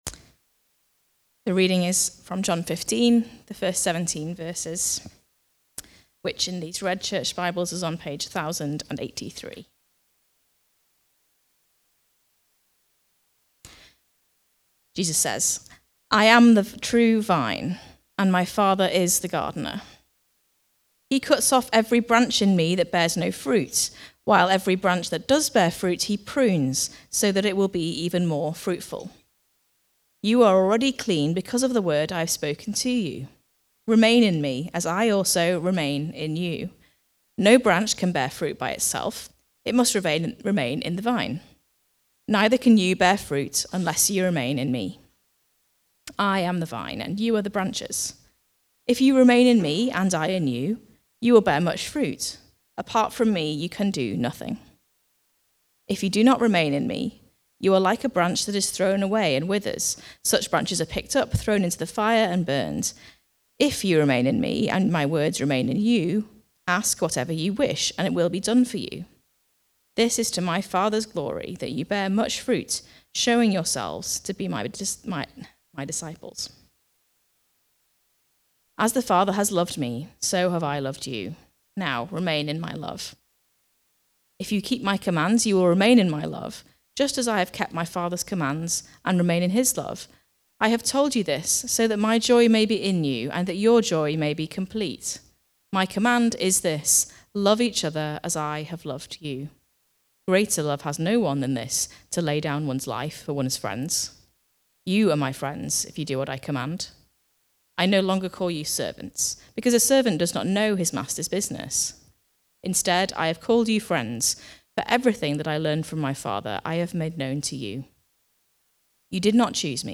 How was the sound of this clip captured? The Vine (John 15:1-17) from the series Comfort and Joy - John 13-17. Recorded at Woodstock Road Baptist Church on 16 February 2025.